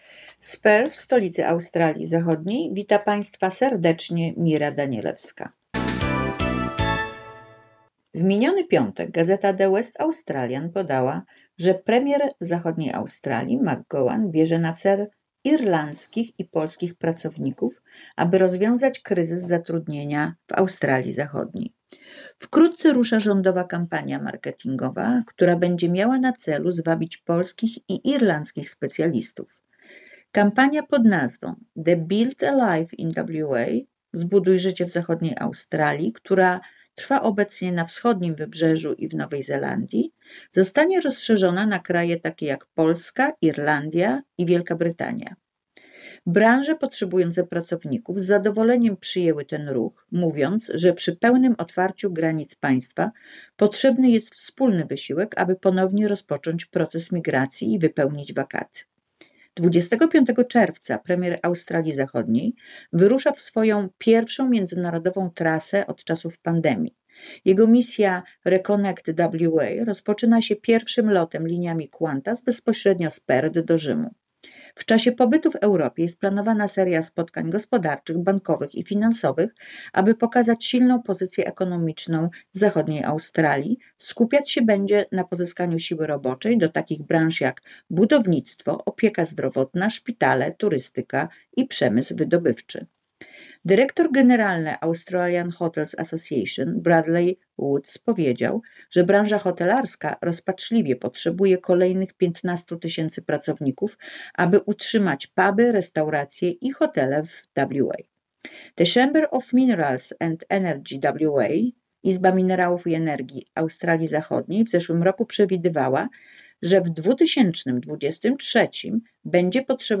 W korespondencji z Perth